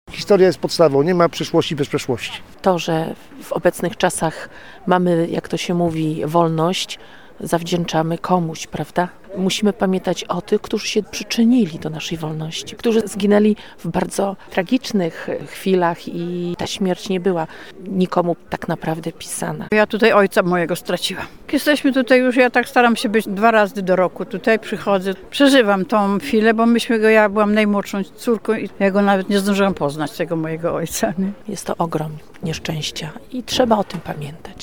Nie zapominamy o tej ogromnej tragedii – mówili zgromadzeni na uroczystości.